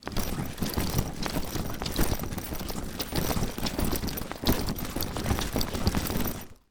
Duffle Bag Rattle Sound
household
Duffle Bag Rattle